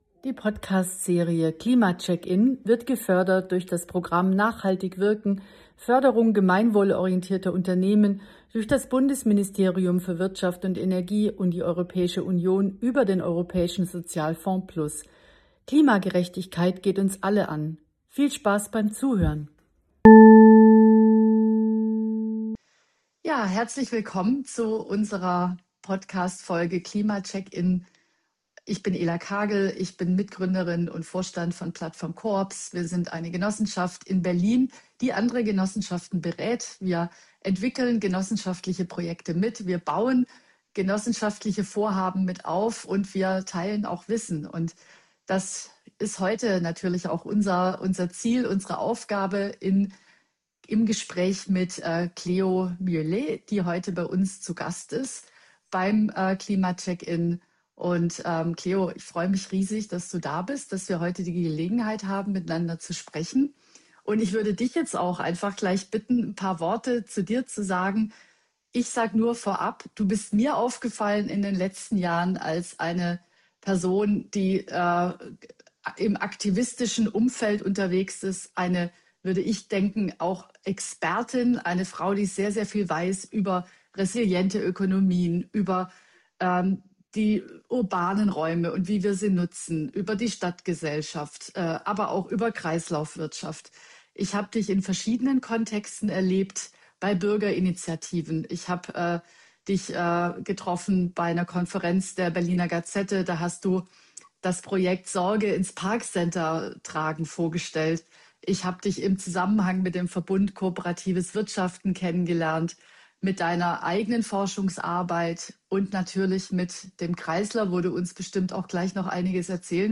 Hier könnt Ihr das Gespräch als Podcast anhören: